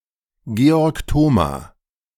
Georg Thoma (German pronunciation: [ˈɡeːɔʁk ˈtoːmaː]
De-Georg_Thoma.ogg.mp3